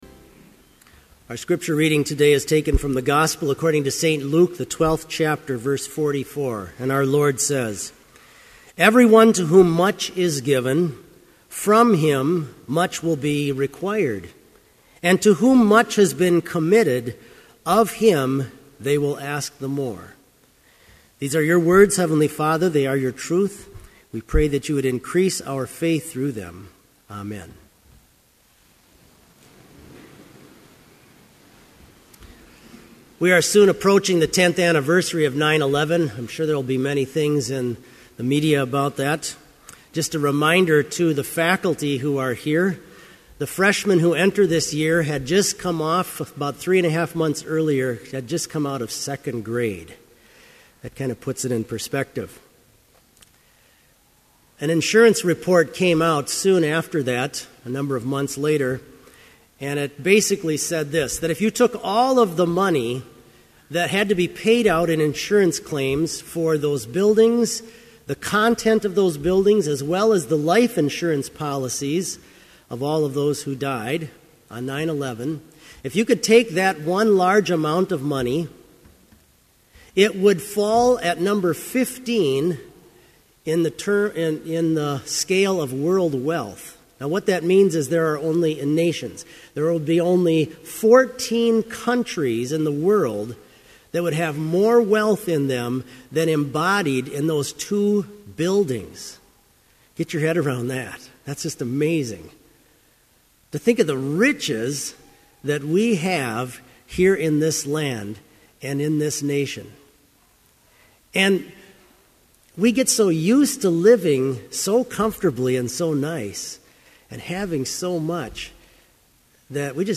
Complete service audio for Chapel - August 26, 2011